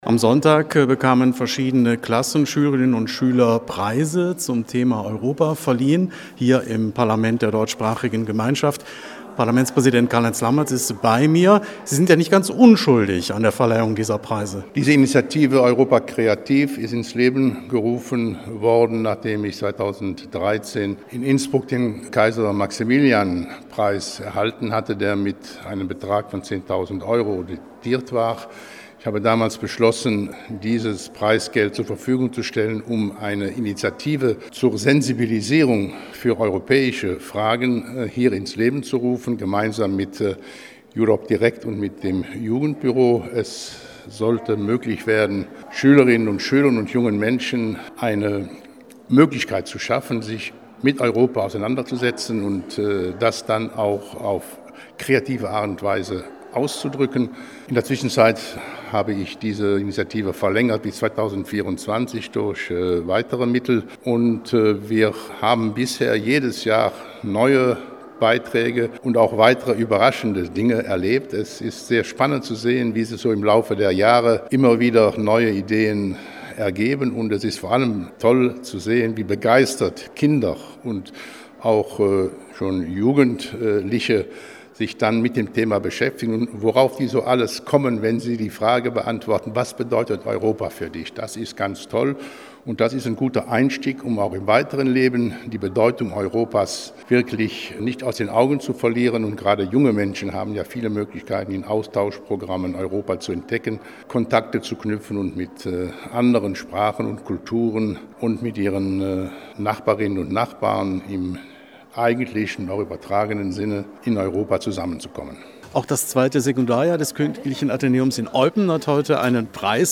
Am Sonntag (22.05.2022) war Preisverleihung im Parlament der Deutschsprachigen Gemeinschaft.